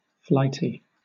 Ääntäminen
Southern England
IPA : /ˈflaɪti/